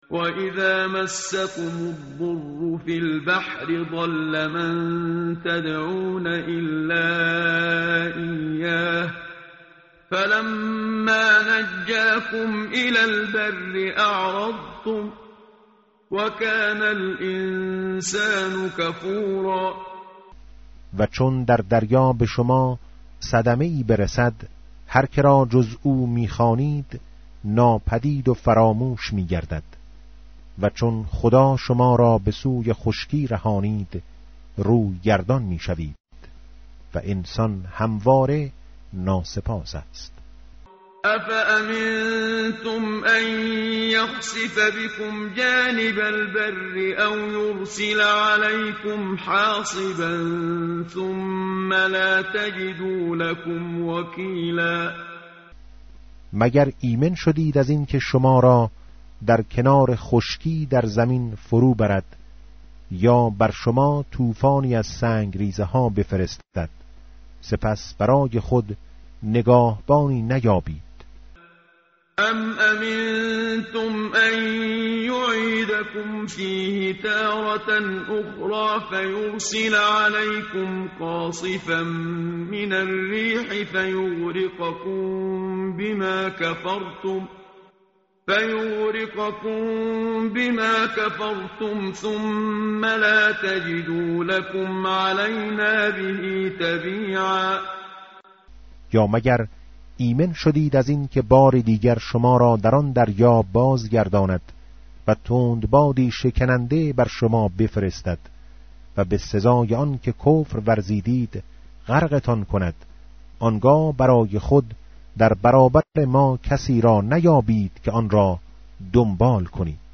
tartil_menshavi va tarjome_Page_289.mp3